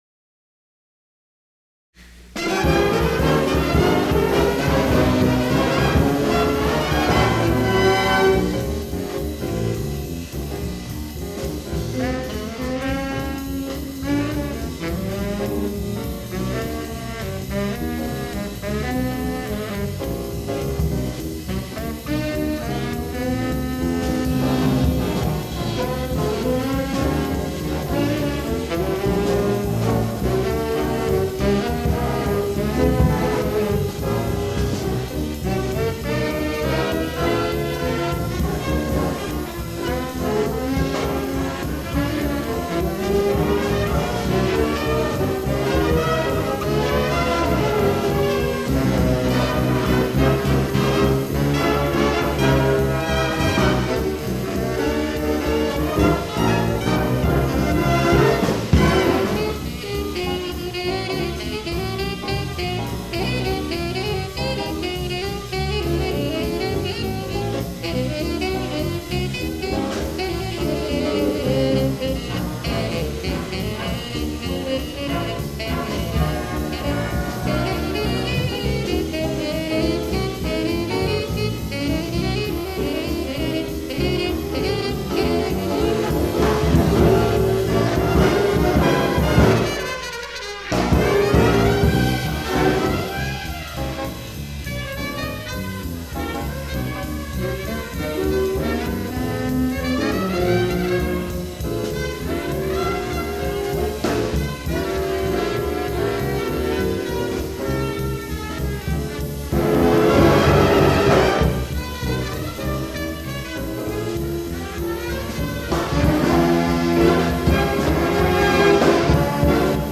This page lists many of my original charts (compositions and arrangements) for big band.
In these cases, I generated MIDI-based recordings using Dorico and some nice sample libraries. I then used an audio editor to add solos (which I played from a keyboard) and piano comping as needed.